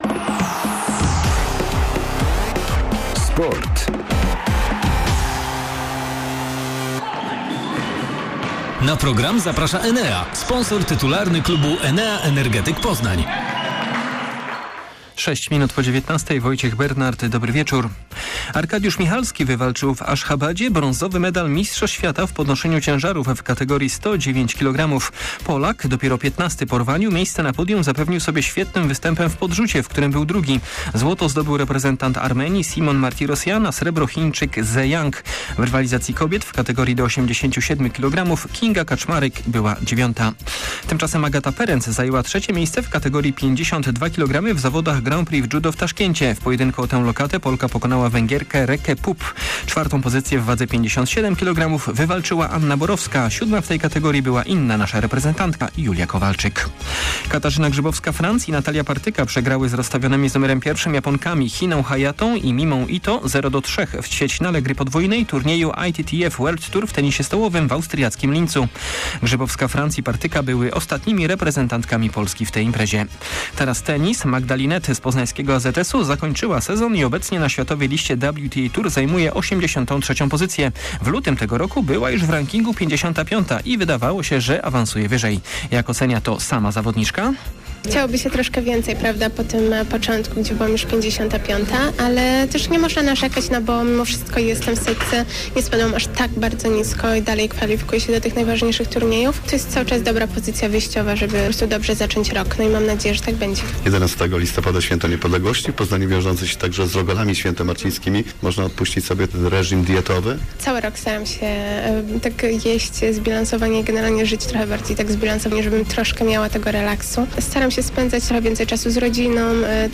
09.11. SERWIS SPORTOWY GODZ. 19:05
W naszym piątkowym serwisie między innymi rozmowa z tenisistką AZS Poznań Magdą Linette, która zakończyła sezon na światowych kortach. Zbadamy także nastroje wśród piłkarzy pierwszoligowej Warty Poznań przed meczem ze Stomilem Olsztyn.